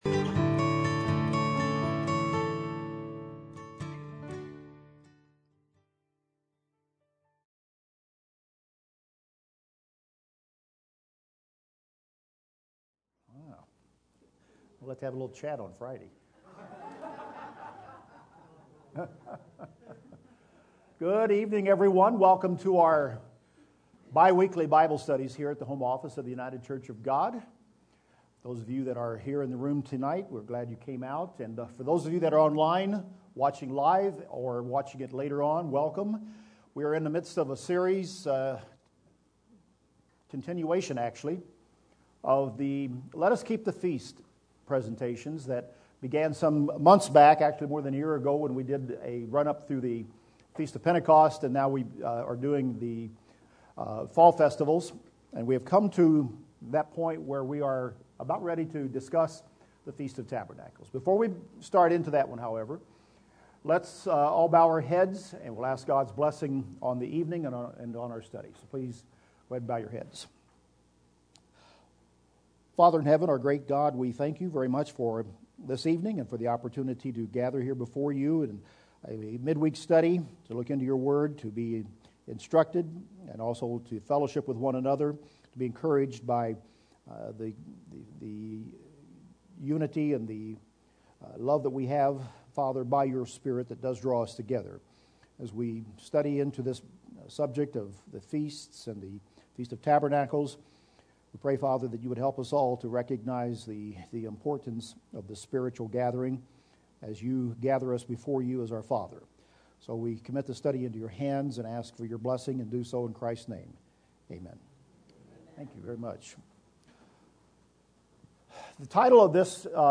This is part 11 in the Beyond Today Bible study series: Let Us Keep the Feasts.